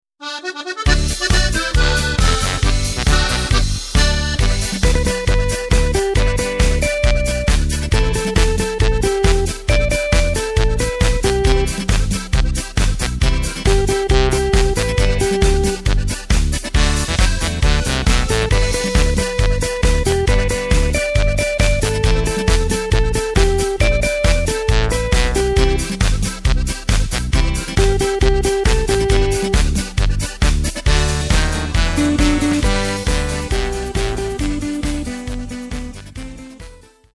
Takt:          4/4
Tempo:         136.00
Tonart:            G